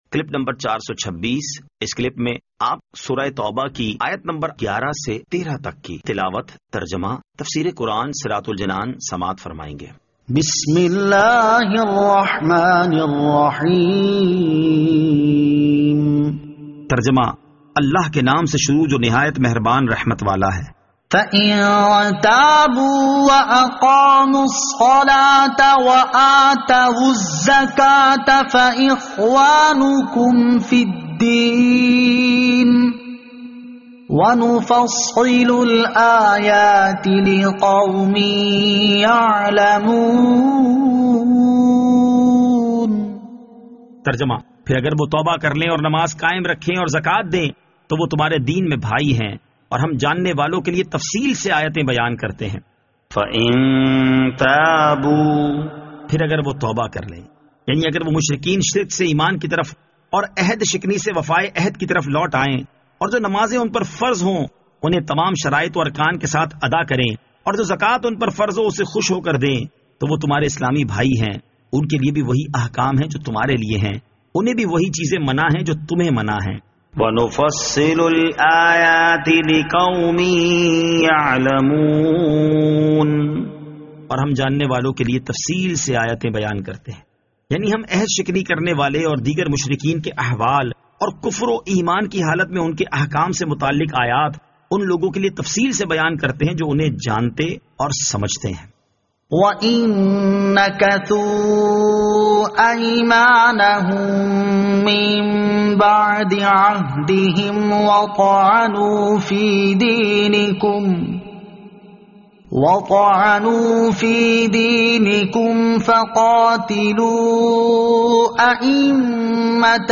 Surah At-Tawbah Ayat 11 To 13 Tilawat , Tarjama , Tafseer